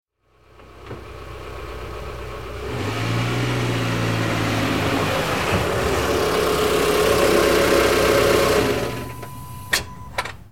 دانلود آهنگ ماشین 10 از افکت صوتی حمل و نقل
جلوه های صوتی
دانلود صدای ماشین 10 از ساعد نیوز با لینک مستقیم و کیفیت بالا